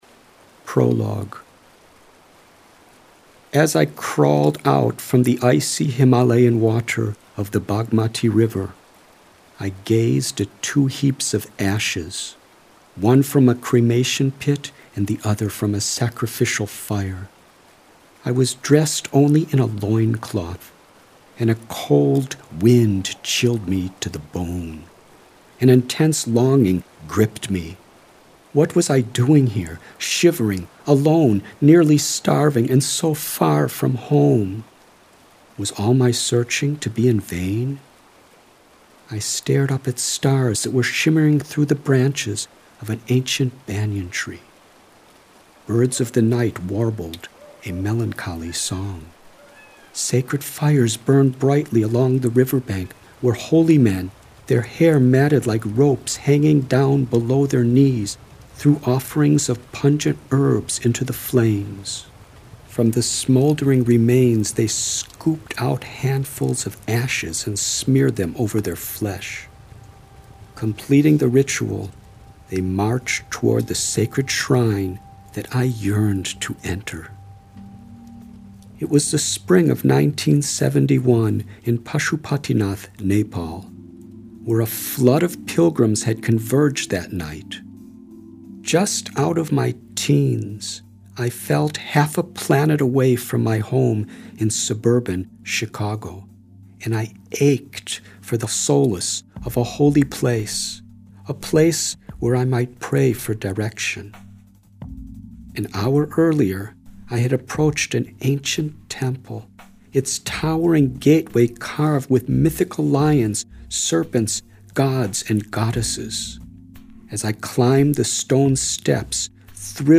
Supplemented with atmospheric music and an array of sound effects reminiscent of classic radio dramas, this audiobook will fascinate listeners for hours.